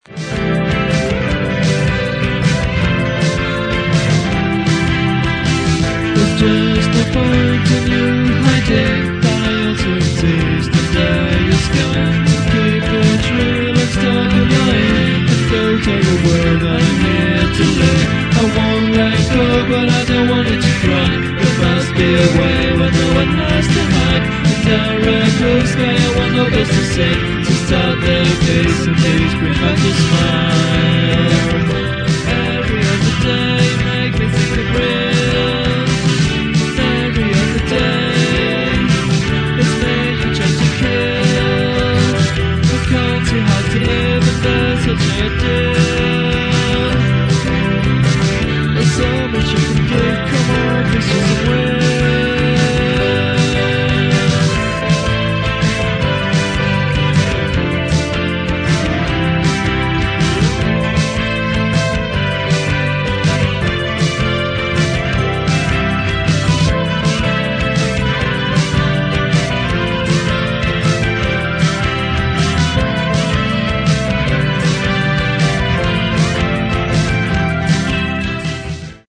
OK, let's be fair, I think the singing is atrocious, the music mostly rubbish, and the lyrics cringeworthy.